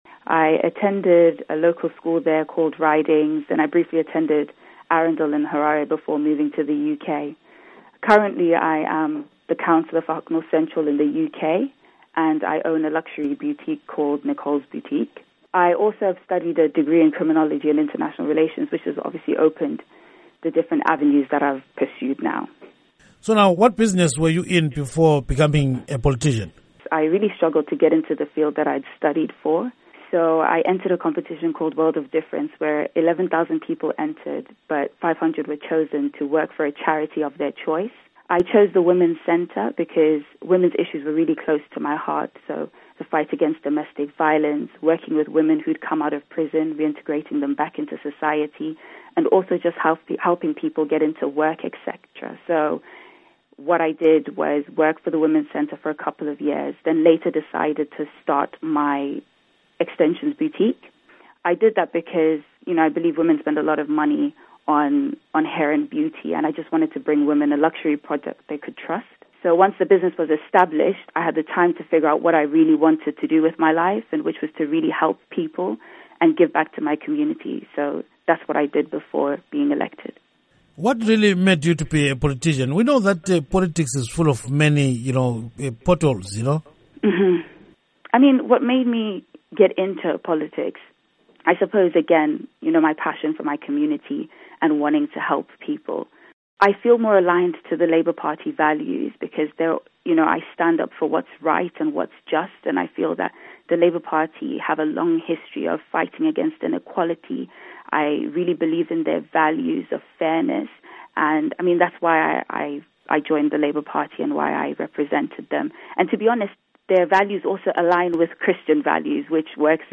Interview With Nicolle Ndiweni on Becoming British Councillor